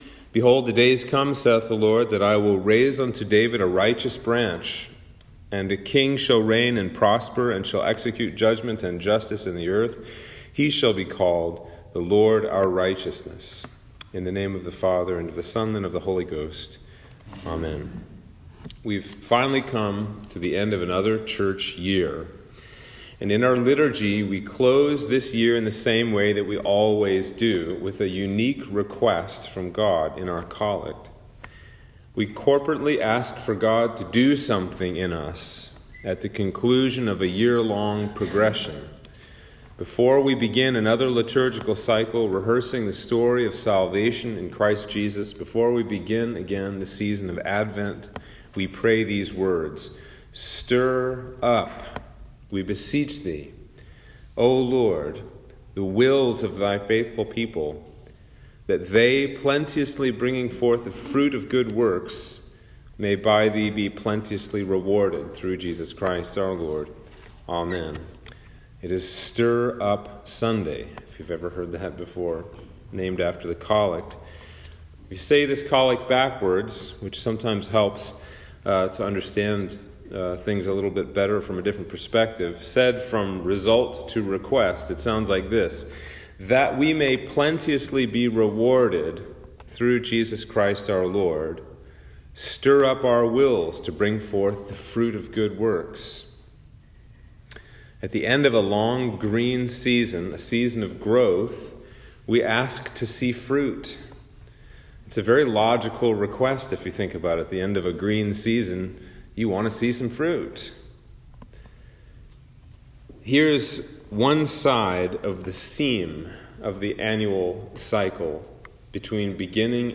Sermon for the Sunday Next Before Advent - 2021